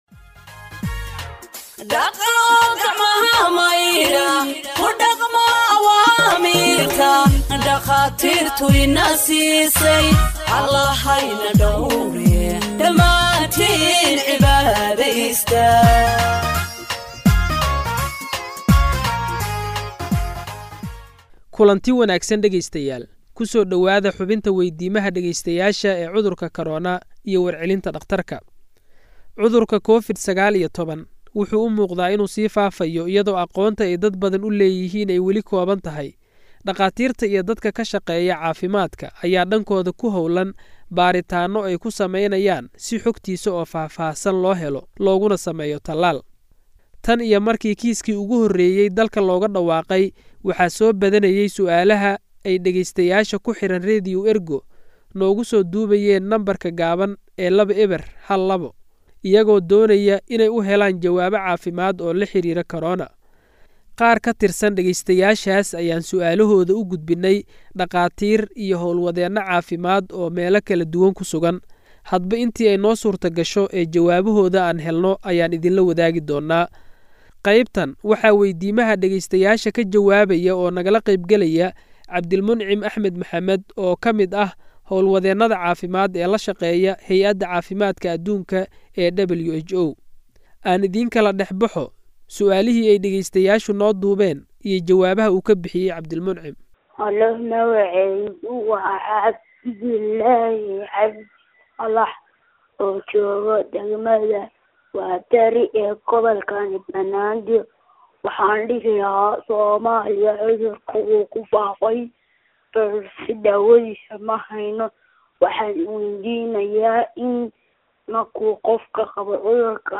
Health expert answers listeners’ questions on COVID 19 (7)
Radio Ergo provides Somali humanitarian news gathered from its correspondents across the country for radio broadcast and website publication.